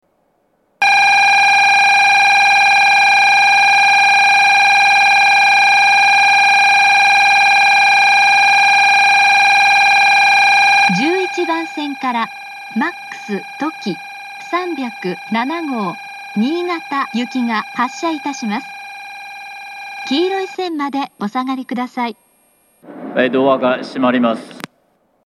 １１番線発車ベル Ｍａｘとき３０７号新潟行の放送です。